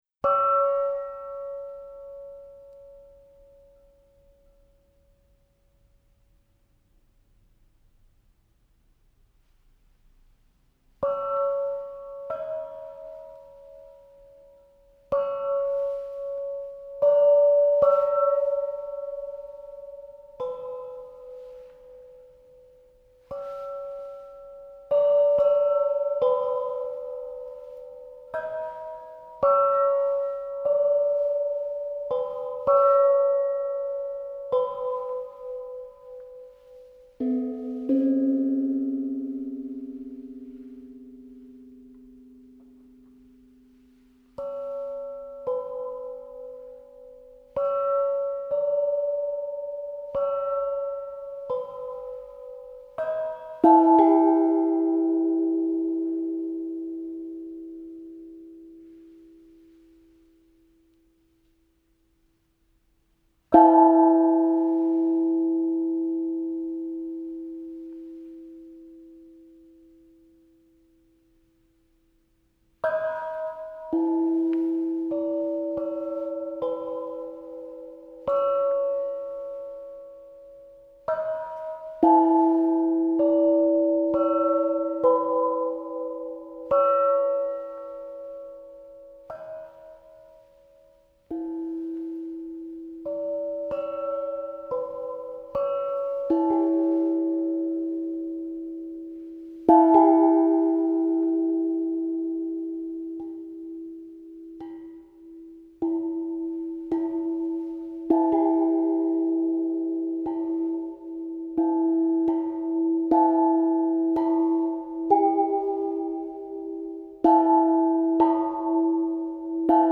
Eine Erzählung in der „Halle“
Rec. 2.6.2016 „Die Halle“ Dresden